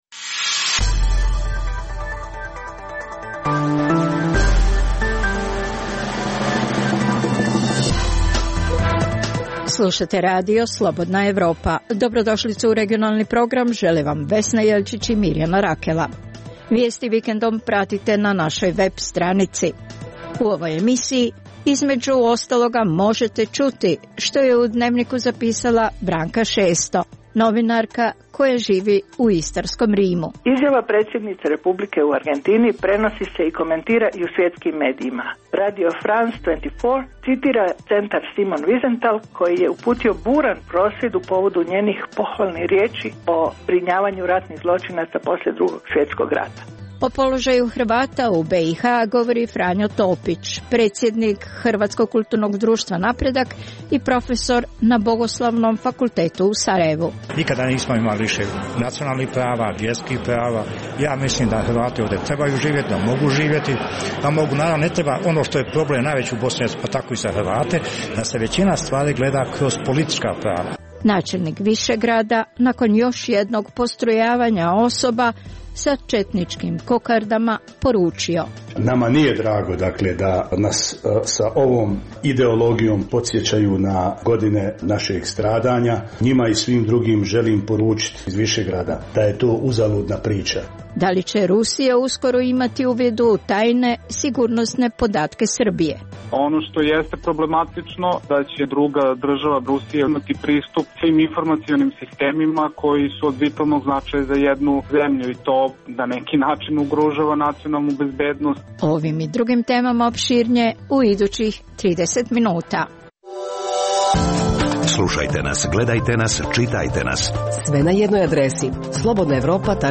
Preostalih pola sata emisije, nazvanih "Tema sedmice" sadrži analitičke teme, intervjue i priče iz života, te rubriku "Dnevnik", koji poznate i zanimljive osobe vode za Radio Slobodna Evropa vode